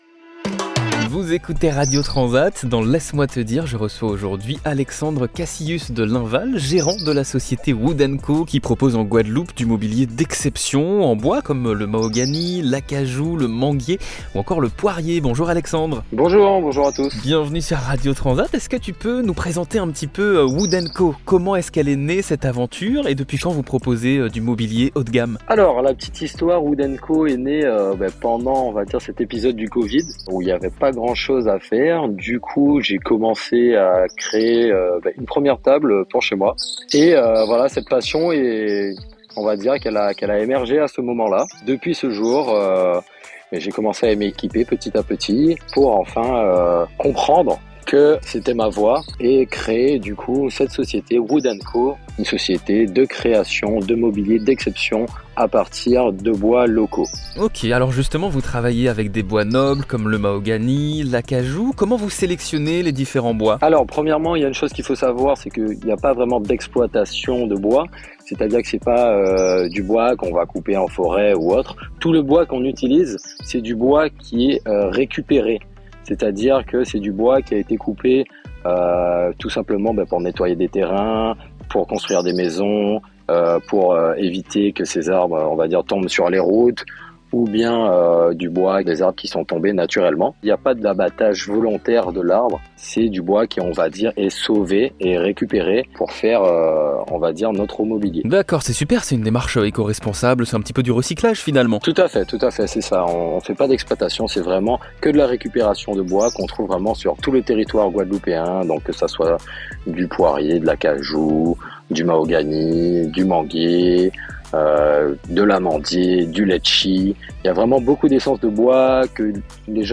Dans cette interview